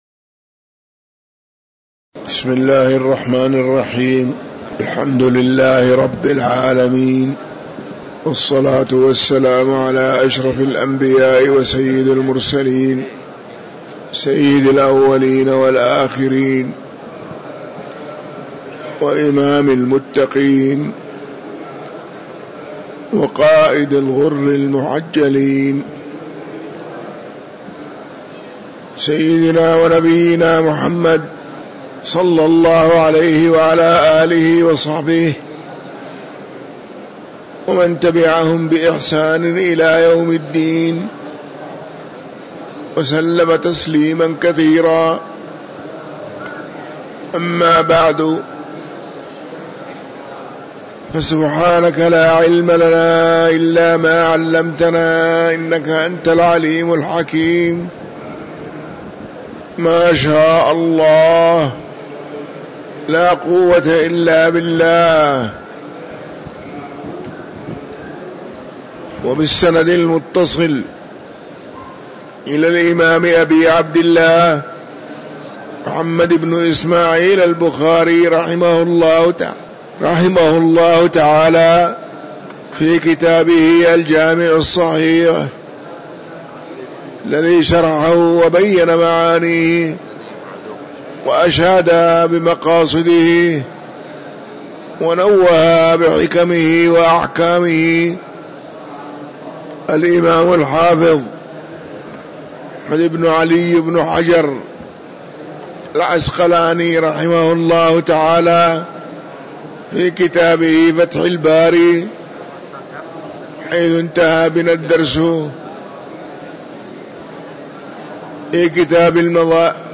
تاريخ النشر ٣ شعبان ١٤٤٥ هـ المكان: المسجد النبوي الشيخ